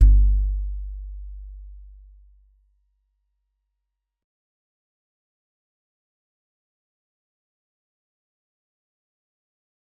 G_Musicbox-G1-mf.wav